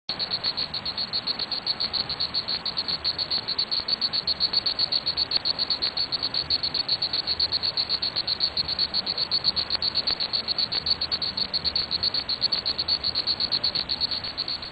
Title: Sound of Mangrove Forest
Description: This is the bug sound commonly heard in mangroves.